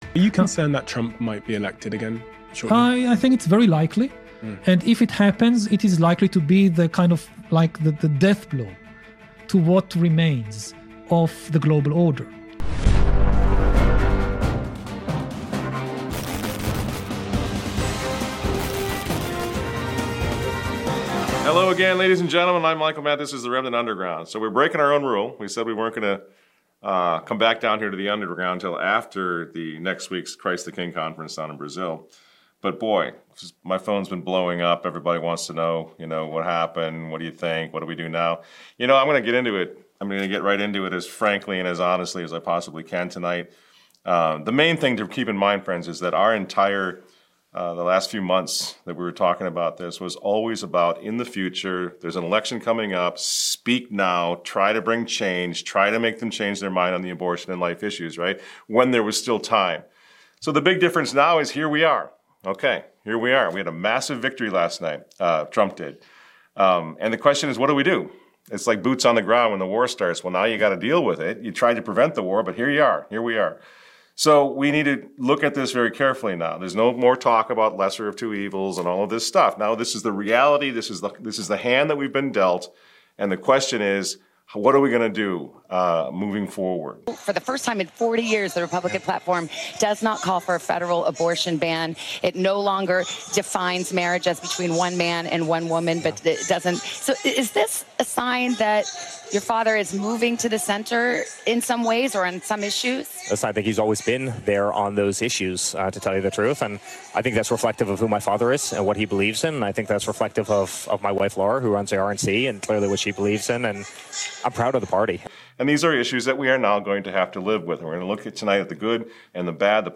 A weekly phone conversation